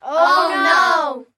Звук детского хора